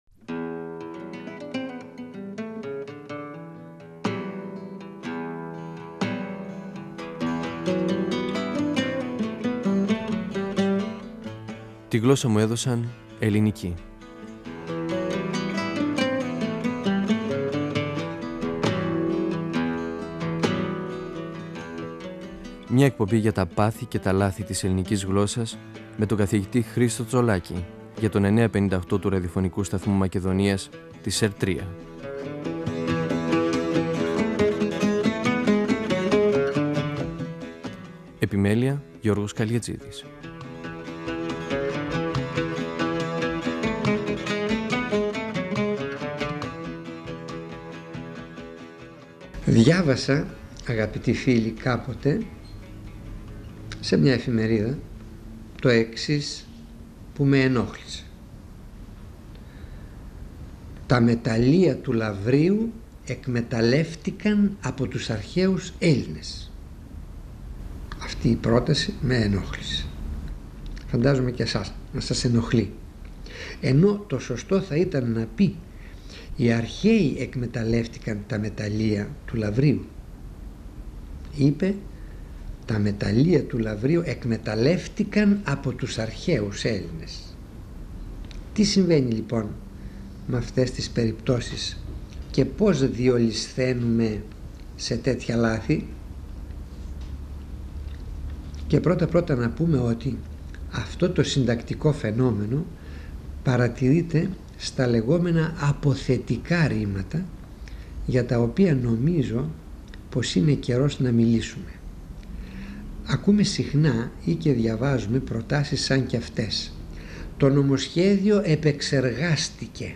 Ο γλωσσολόγος Χρίστος Τσολάκης (1935-2012) μιλά για τα ρήματα ενεργητικής διάθεσης που έχουν τύπο παθητικής φωνής.
Νησίδες & 9.58fm, 1999 (πρώτος, δεύτερος, τρίτος τόμος), 2006 (τέταρτος τόμος, πέμπτος τόμος). 958FM Αρχειο Φωνες Τη Γλωσσα μου Εδωσαν Ελληνικη "Φωνές" από το Ραδιοφωνικό Αρχείο Εκπομπές ΕΡΤ3